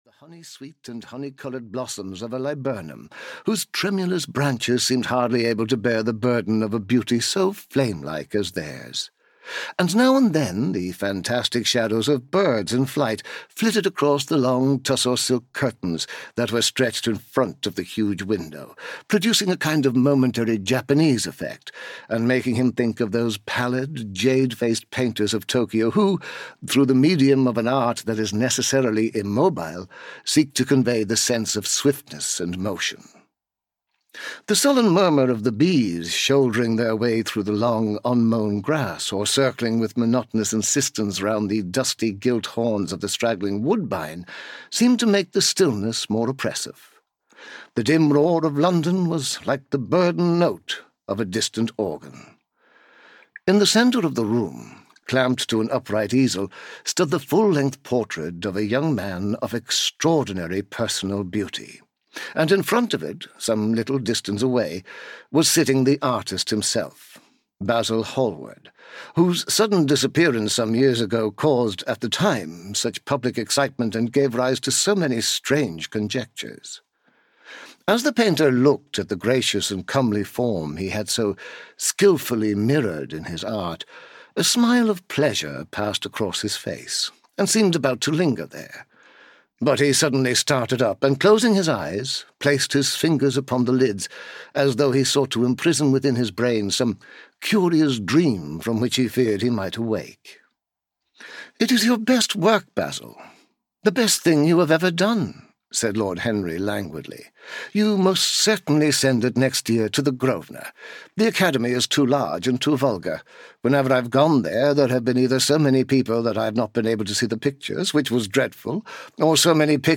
The Picture of Dorian Gray (EN) audiokniha
Ukázka z knihy